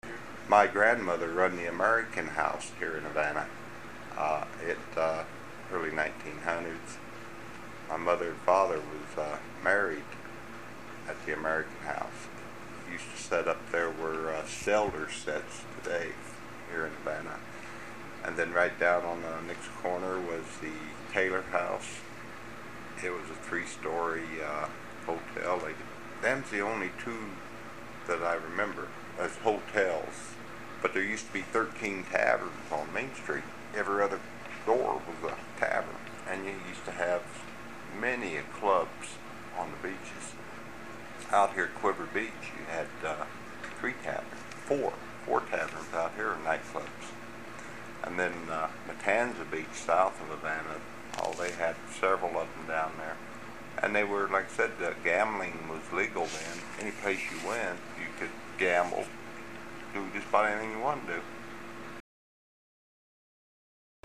HTR Oral History, 08/18/1